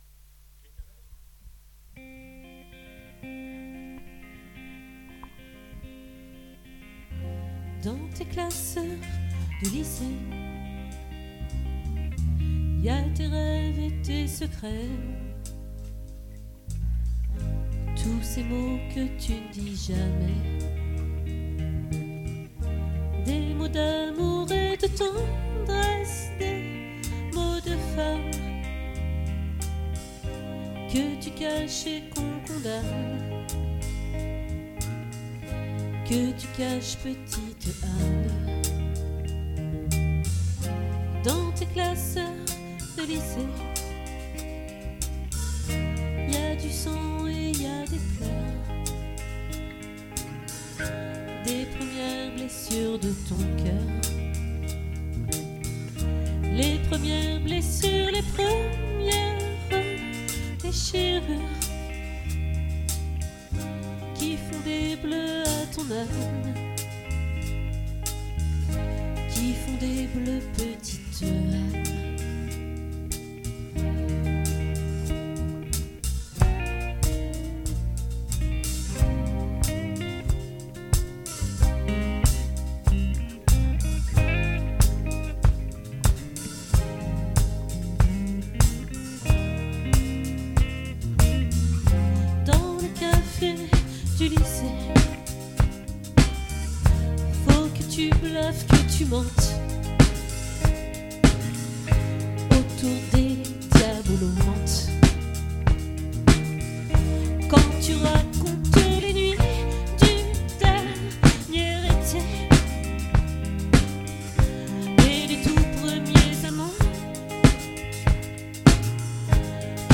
🏠 Accueil Repetitions Records_2023_03_29_OLVRE